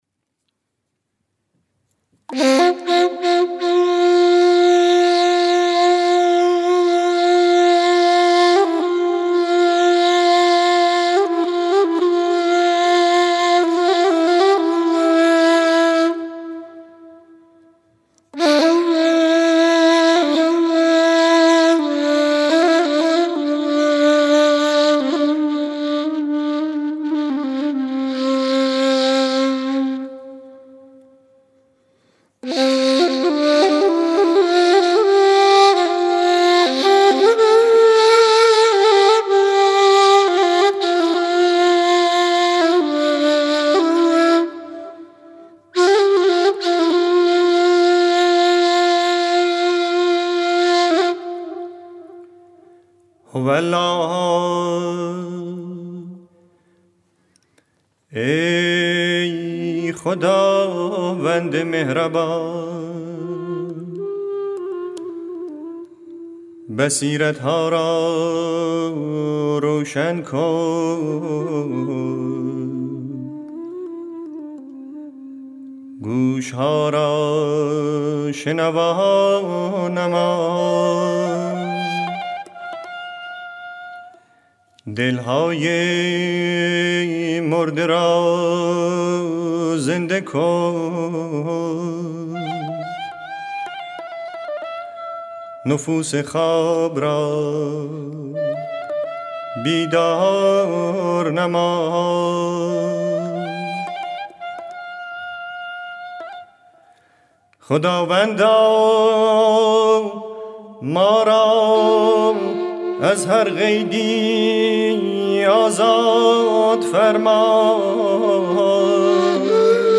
مجموعه مناجات های فارسی همراه با موسیقی